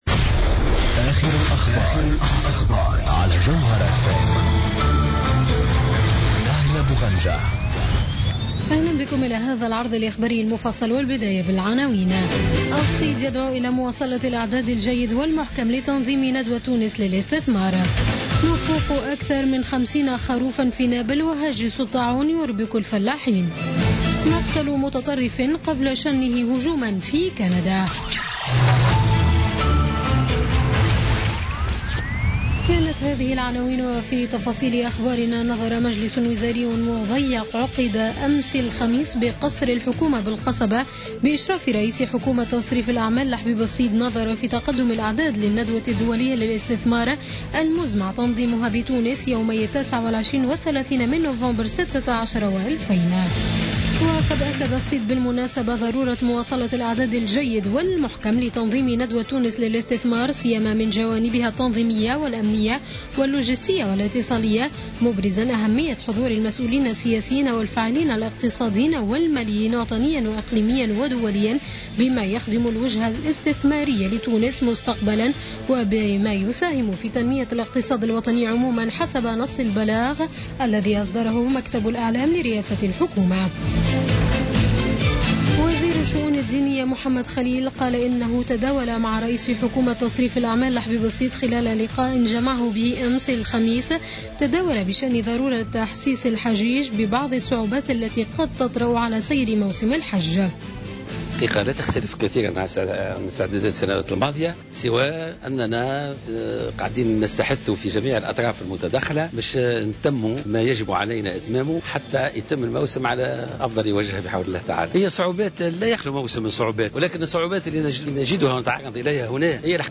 Journal Info 00h00 du vendredi 12 Août 2016